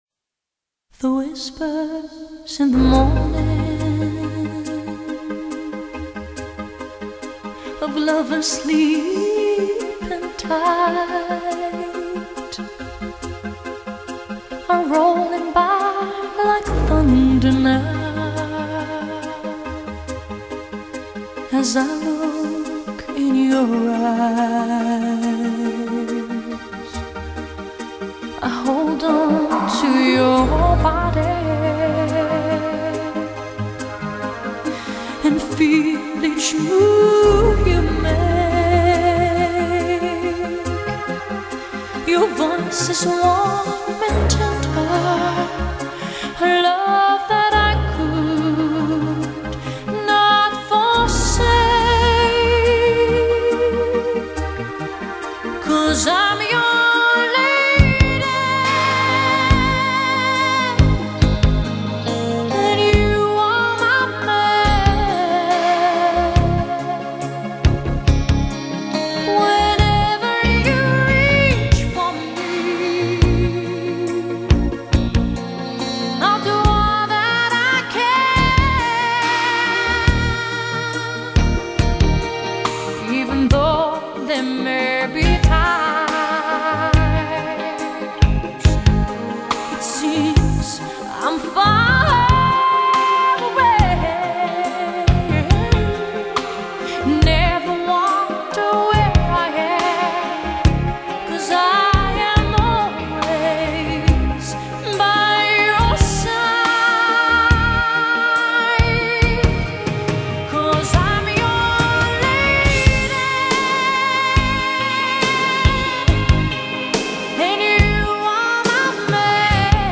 音乐类型: 试音碟